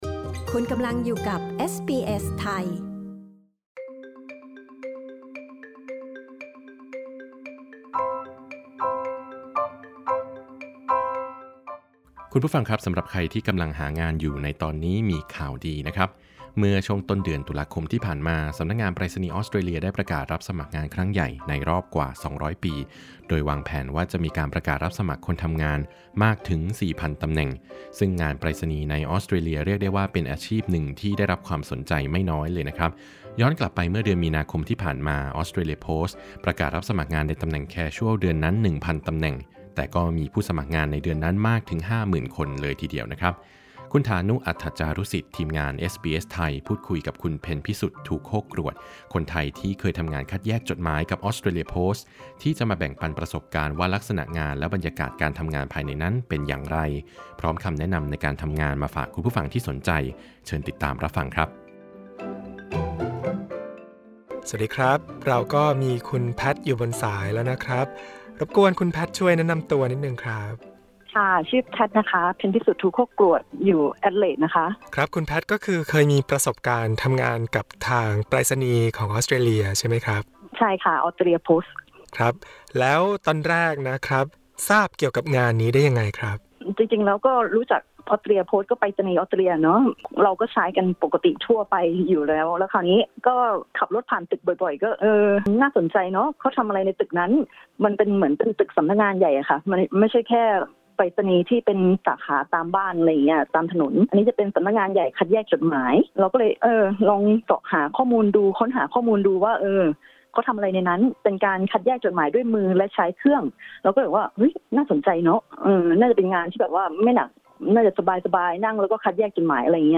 ฟังสัมภาษณ์เรื่องนี้เป็นภาษาไทย